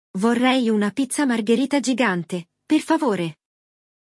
Neste episódio, você acompanha um diálogo entre um casal no mercado escolhendo os ingredientes para fazer uma pizza margherita gigante. Além de aprender vocabulário essencial sobre comida e compras, você vai entender como usar os verbos “rimanere” (ficar) e “scegliere” (escolher) no dia a dia.